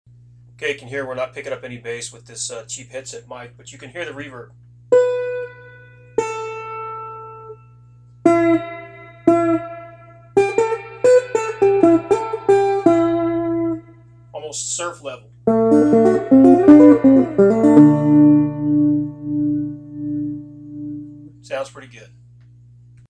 Input is from a 1992 USA Strat Deluxe Plus.
Output from my little homebuilt amp went to a 1966 Guild Thunder I.
Despite the rat's nest of wires and total lack of shielding, there was remarkably little hum. The old Guild seemed to really like the buttery sound of the Fender reverb tank.
I apologize for the lack of audio quality; I recorded it on my laptop with a cheap headset microphone.
Prototype Reverb
2-surf_reverb.mp3